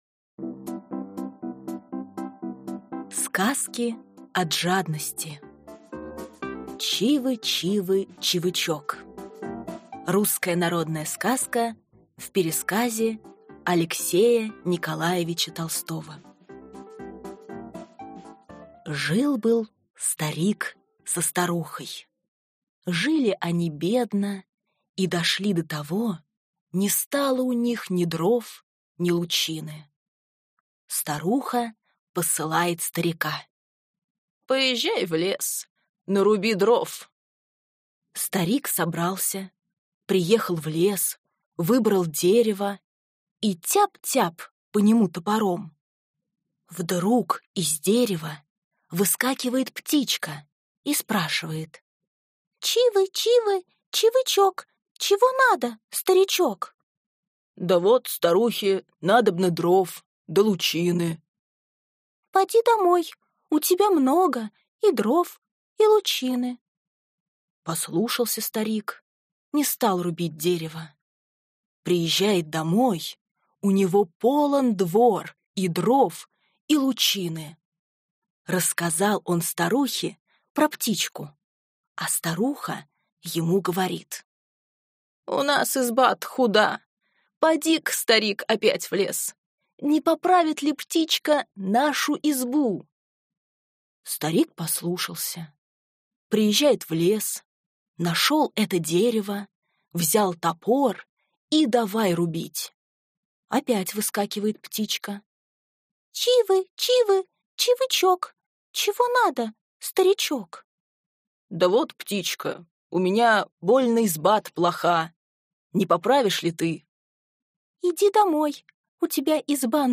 Аудиокнига Сказки от жадности | Библиотека аудиокниг